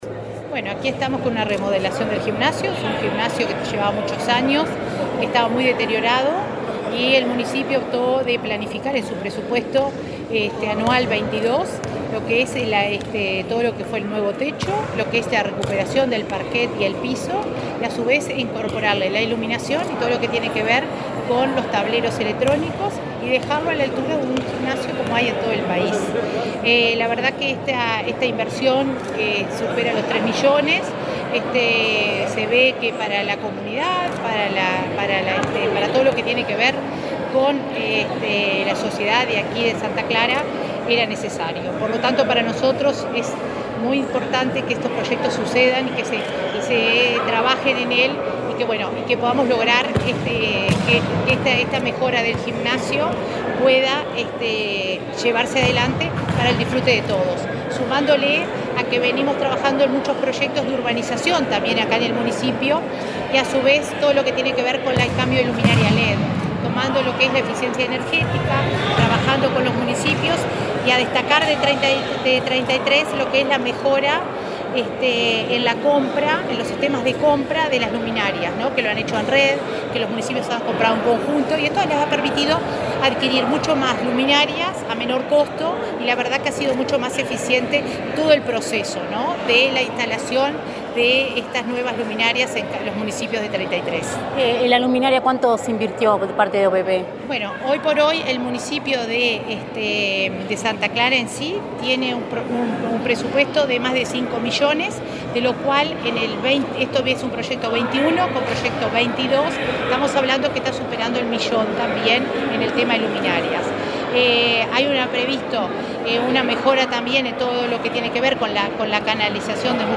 Entrevista a la directora de Descentralización de OPP, María de Lima